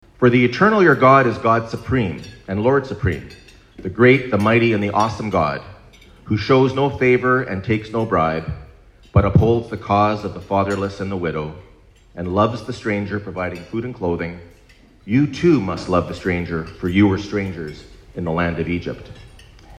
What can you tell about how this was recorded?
The mayor of Belleville hosted the first annual Prayer Breakfast Tuesday morning